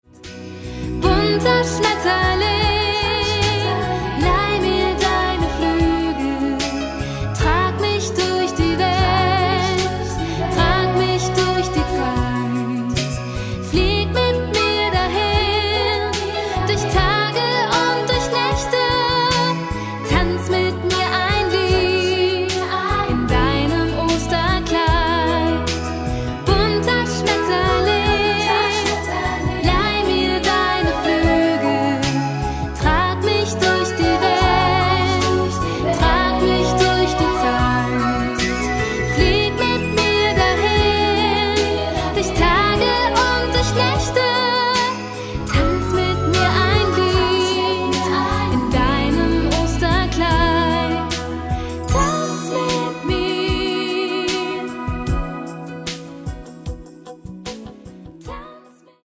• moderner christlicher Popsong